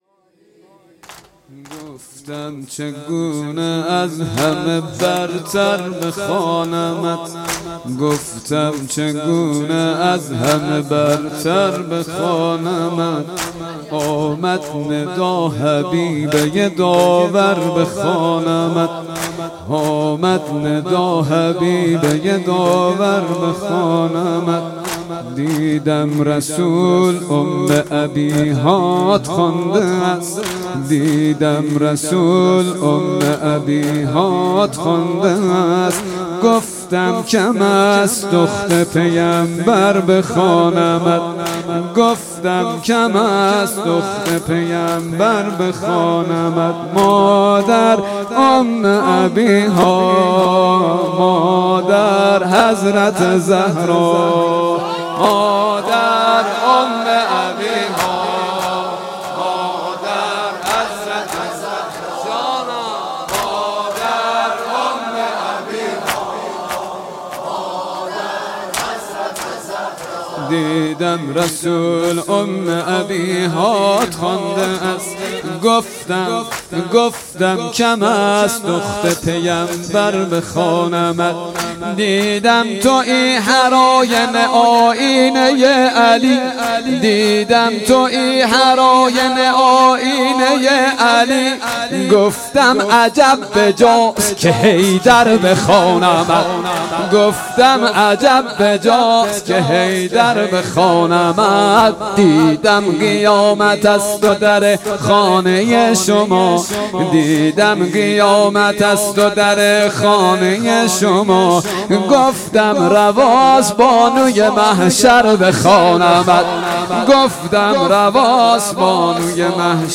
مراسم عزاداری فاطمیه اول